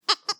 bobbycar_horn-004.wav